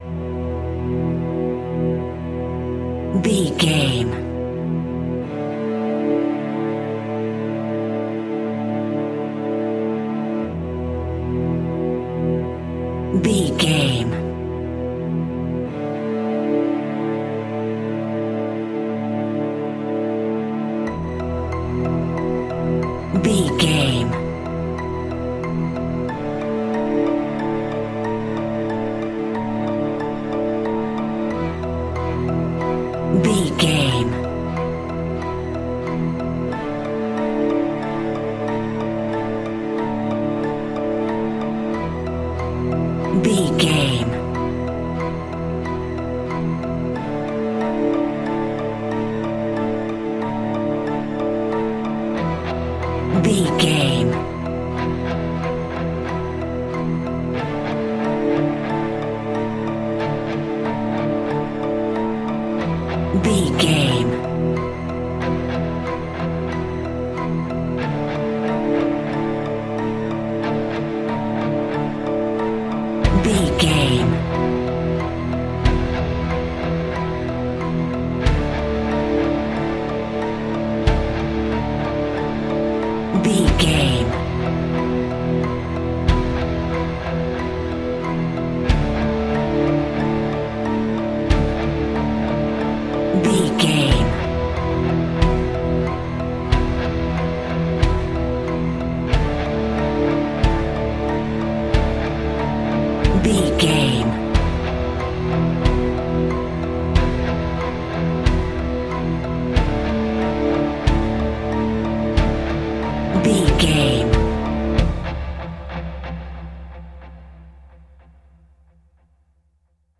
Intense, foreboding and ominous
Aeolian/Minor
Slow
eerie
dreamy
haunting
dramatic
hypnotic
ethereal